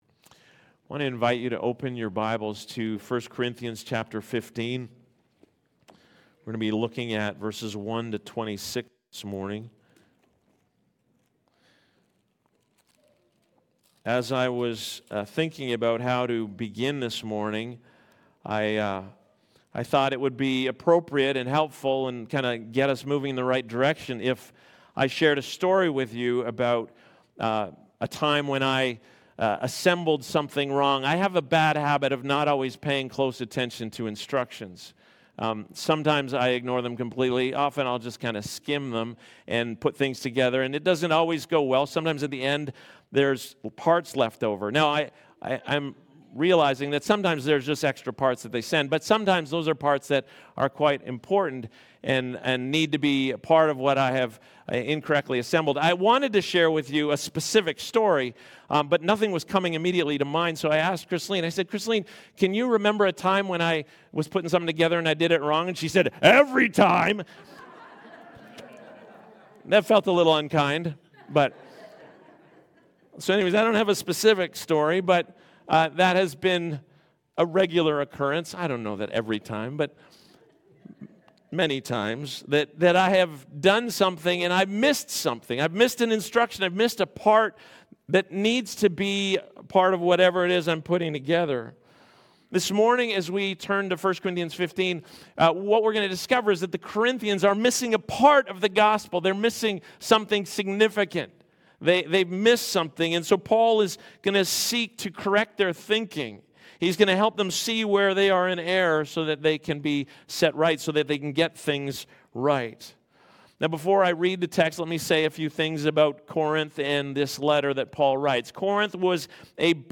Weekly sermon from Sunrise Community Church in Mill Woods, Edmonton, Alberta.